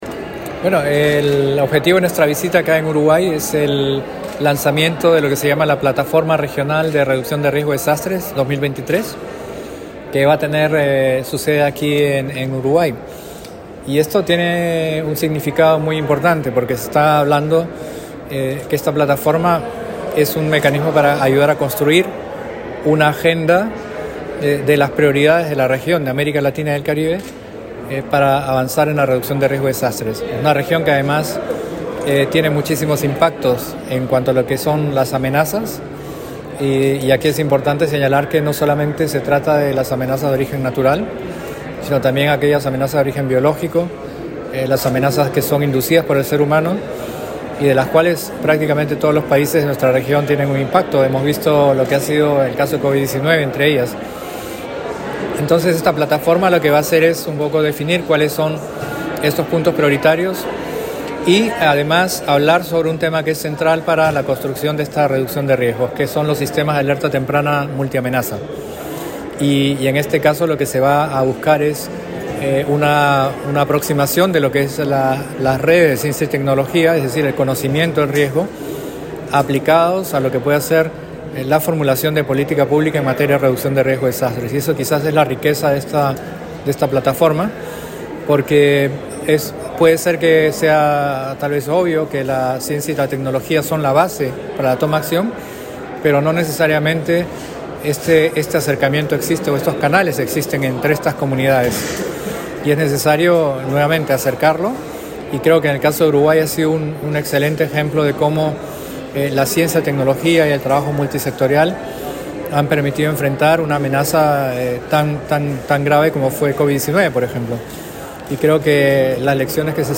Entrevista al jefe de la Oficina de Naciones Unidas para la Reducción del Riesgo de Desastres